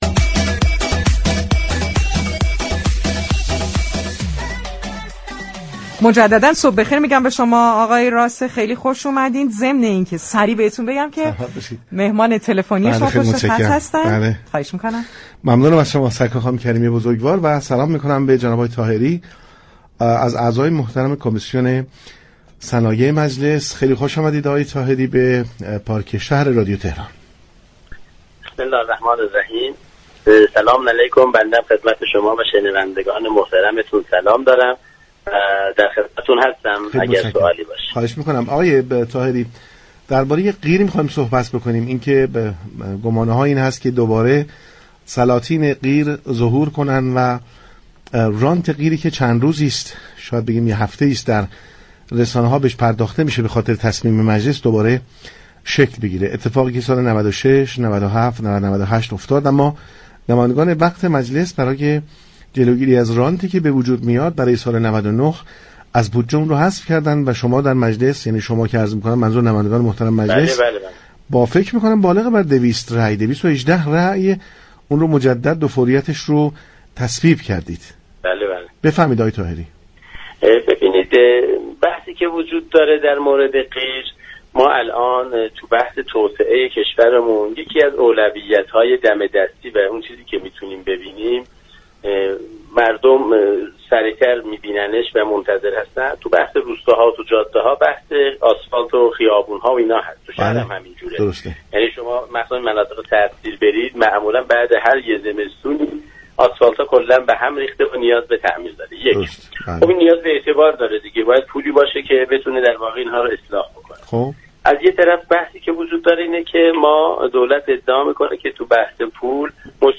مصطفی طاهری، عضو كمیسیون صنایع مجلس شورای اسلامی در گفت‌وگو با پارك شهر رادیو تهران در رابطه با نظارت بر كار پیمانكاران گفت: تنها راه جلوگیری از بروز فساد در استفاده از قیر و آسفالت نظارت تمام وقت بر انجام این فرآیند است.